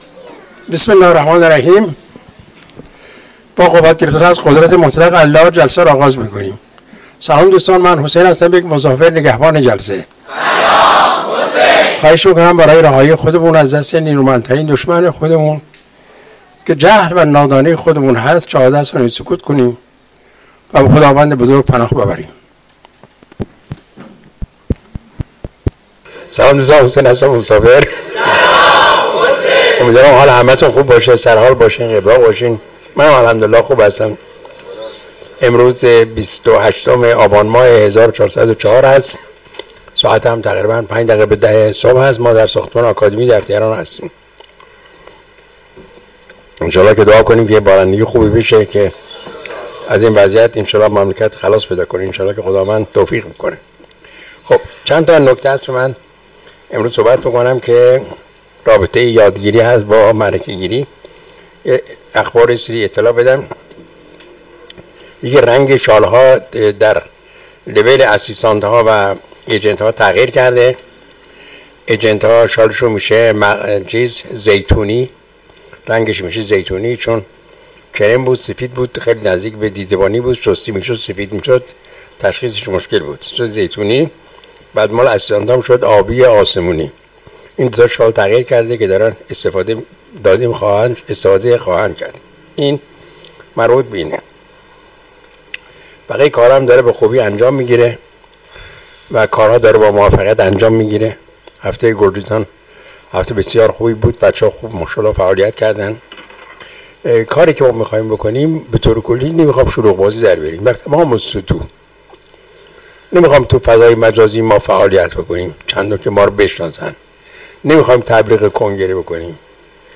کارگاه آموزشی جهان‌بینی؛ رابطه ی یادگیری و معرکه گیری